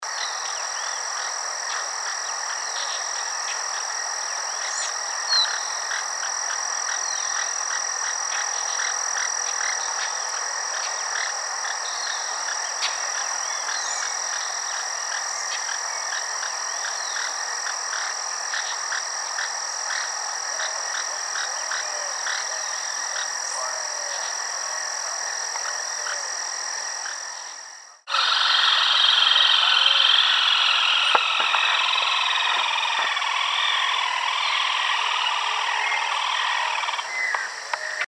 Rufous-sided Crake (Laterallus melanophaius)
Country: Argentina
Location or protected area: Delta del Paraná
Condition: Wild
Certainty: Recorded vocal